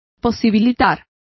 Complete with pronunciation of the translation of enable.